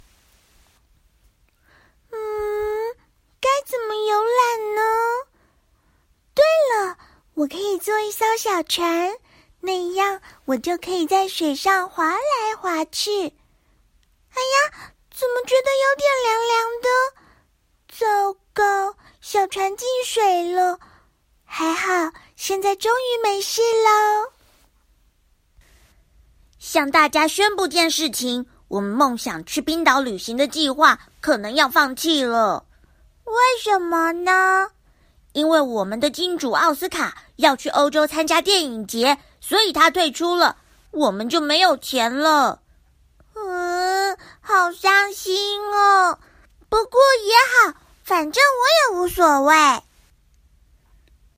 台湾普通话
萌萌可爱